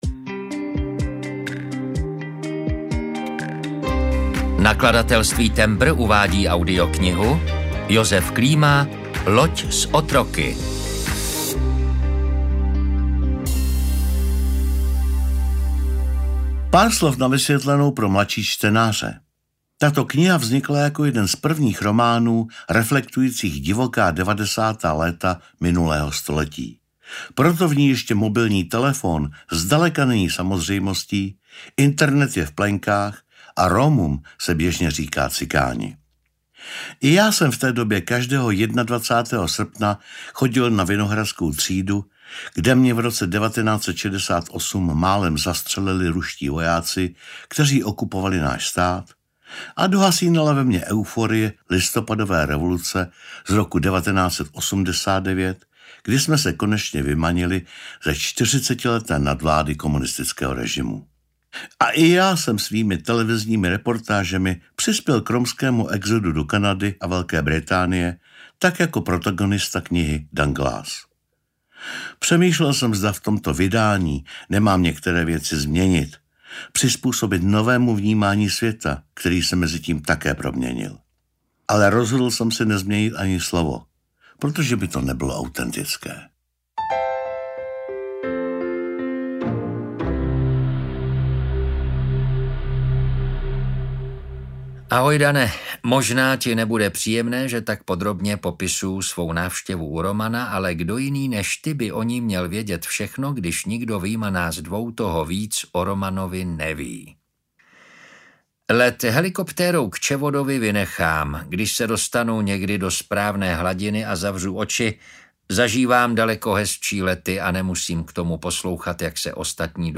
Loď s otroky audiokniha
Ukázka z knihy